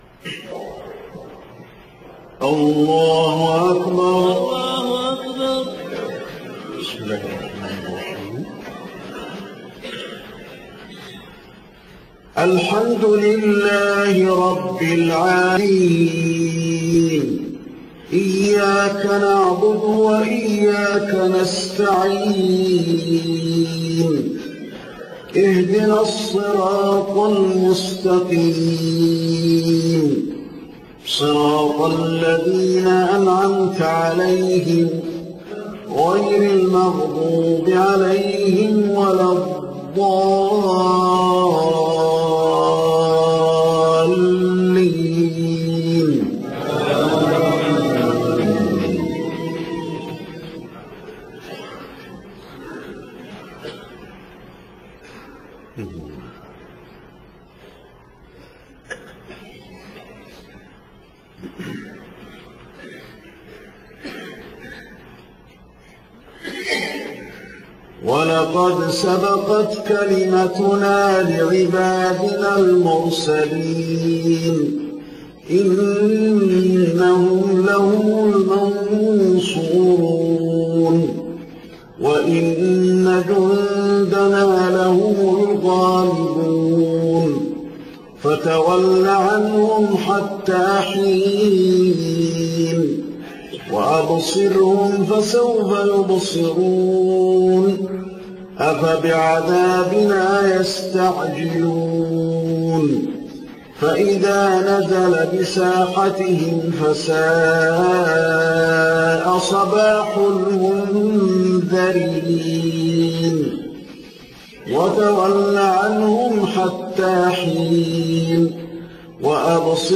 صلاة العشاء 11 محرم 1430هـ خواتيم سورة يس 77-83 والصافات 171-182 > 1430 🕌 > الفروض - تلاوات الحرمين